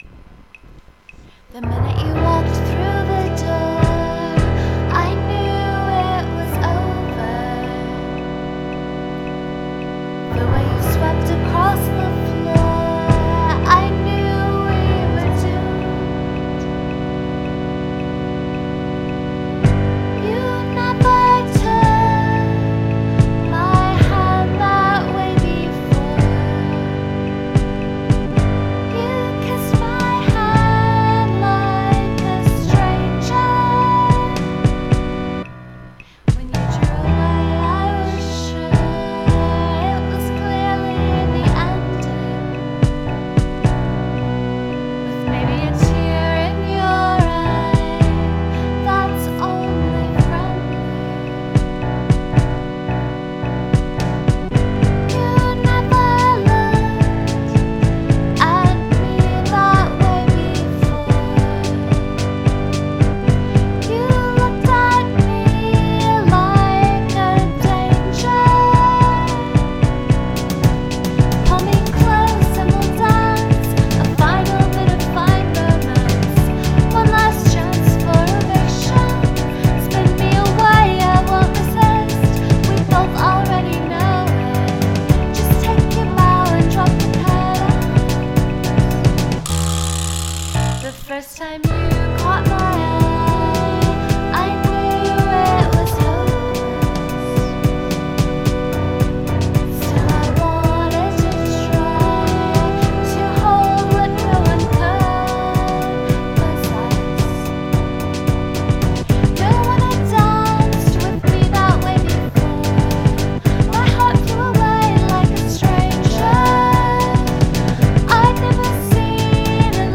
verse: Em Bm
chorus: D A Bm G, D A Bm
The organ makes my life.
• The ratchet rattle is mind-blowing.
• The beat on the second verse is extremely rad.
• Really cool backing vocals on the last refrain.
• Awesome organ glisses at the end.